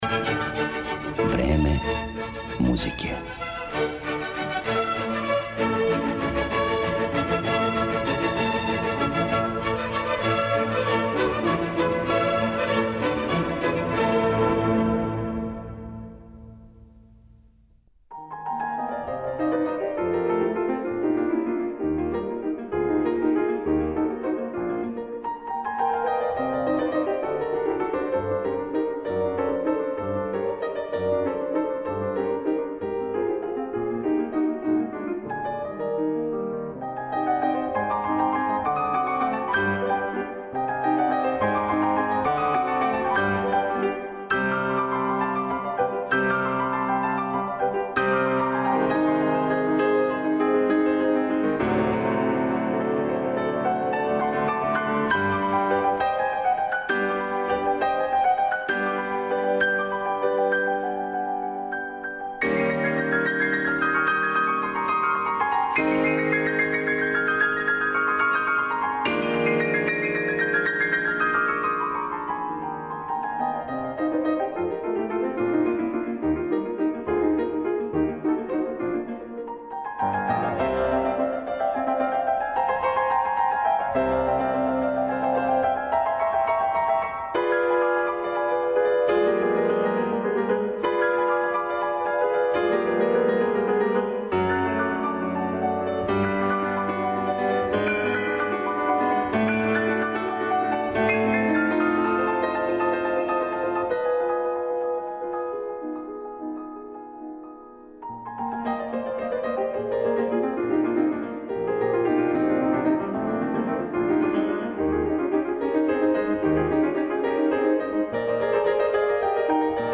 Представићемо га не само кроз његове чувене етиде, које је први из учионице пренео на концертни подијум, већ и виртуозне и маштовите клавирске концерте.